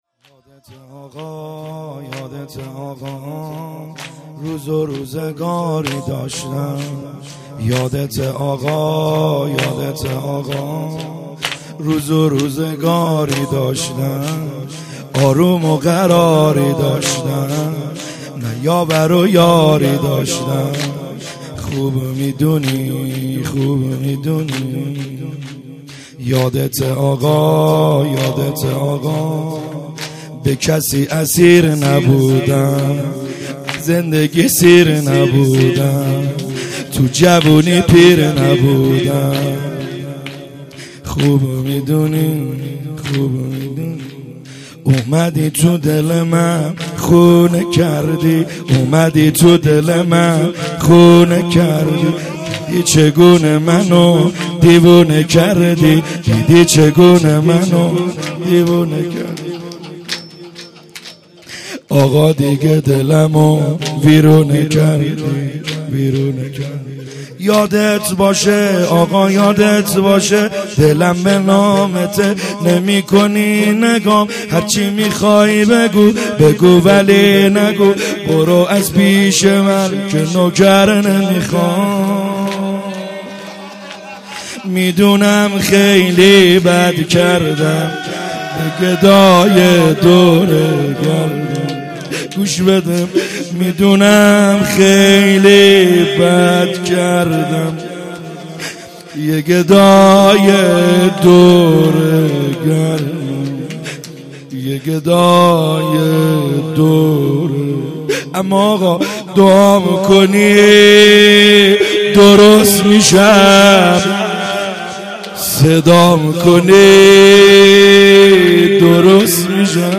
خیمه گاه - بیرق معظم محبین حضرت صاحب الزمان(عج) - شور | یادته اقا روز روزگاری